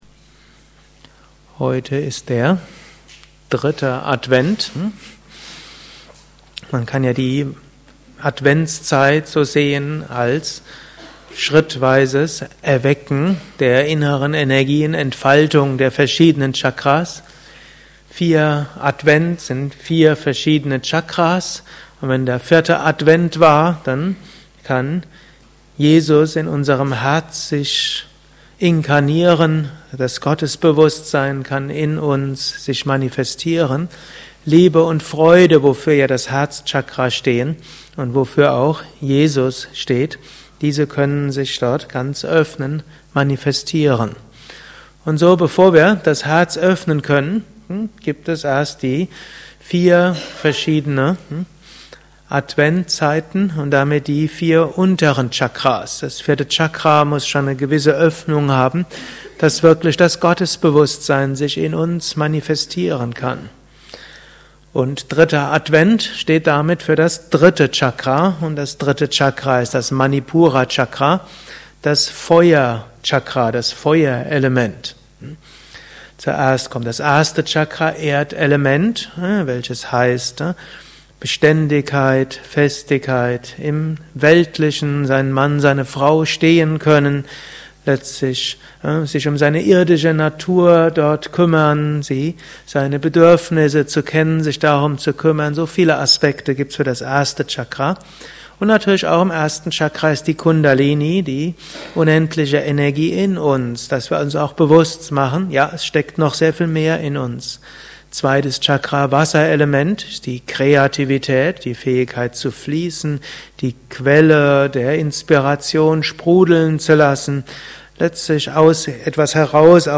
Höre einen kurzen Beitrag über das Manipura Chakra. Dies ist ein kurzer Vortrag als Inspiration für den heutigen Tag